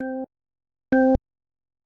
Audio_Sample_-_WL4_Organ.oga.mp3